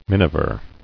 [min·i·ver]